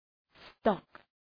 Προφορά
{stɒk}